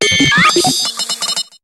Cri de Porygon-Z dans Pokémon HOME.